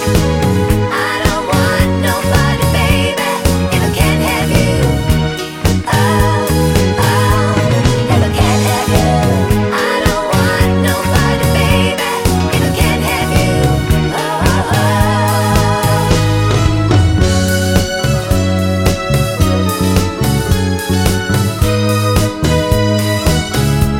Minus Bass, Guitar and Drums Disco 3:00 Buy £1.50